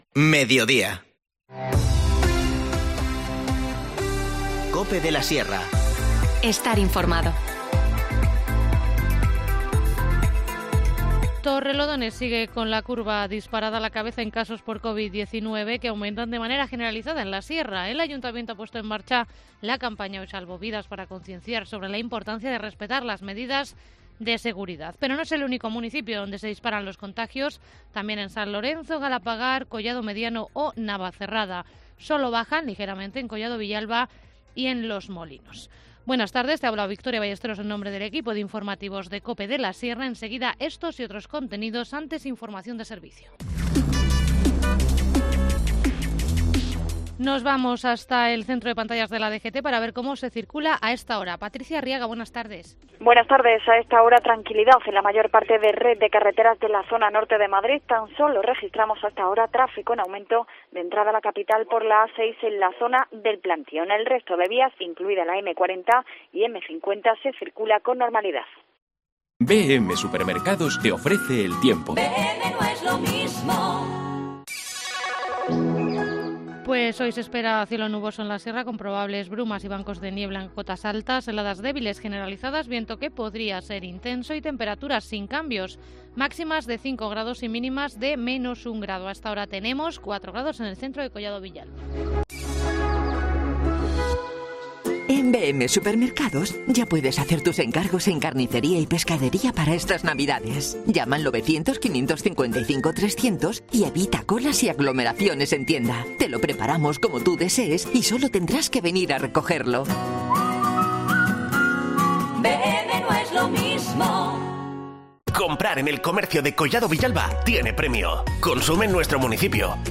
Informativo Mediodía 30 de diciembre